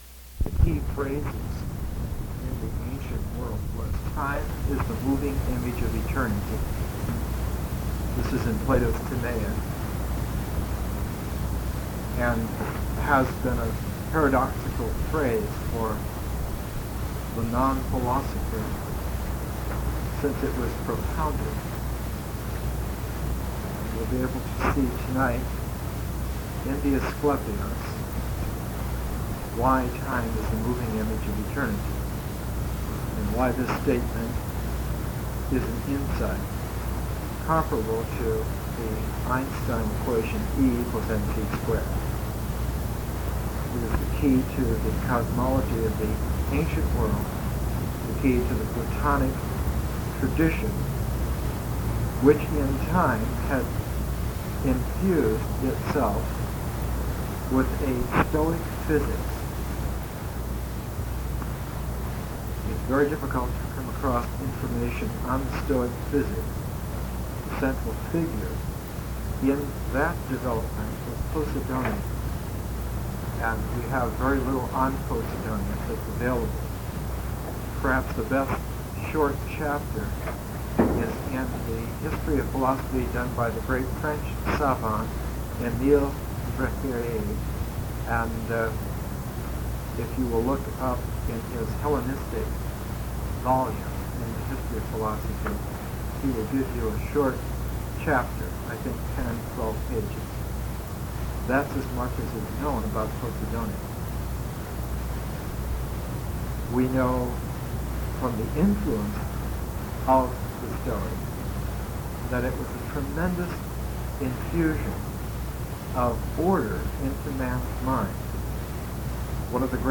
Presentations are 1-1.5 hours: an initial session of 30-45 minutes, an intermission for discussion or contemplation, and a second 30-45 minute session.